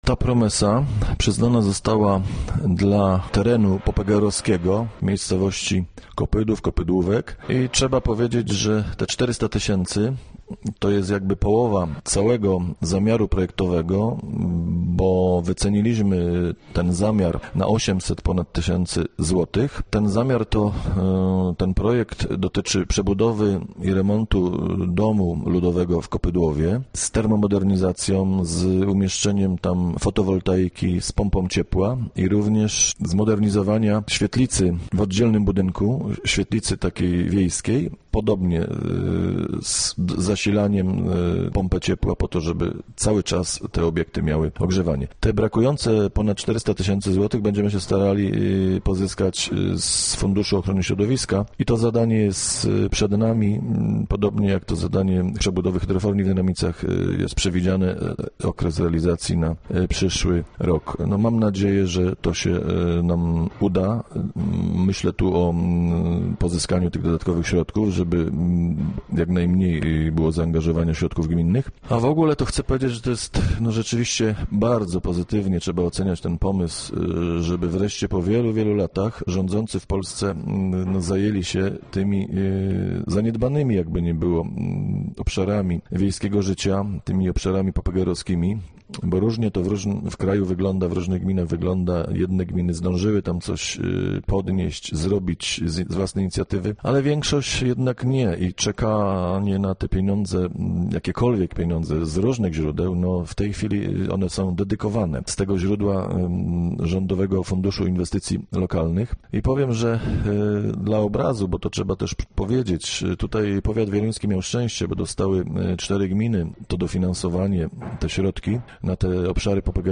Ta promesa została przyznana dla terenu popegeerowskiego w miejscowości Kopydłów – mówi Aleksander Owczarek, wójt gminy Biała.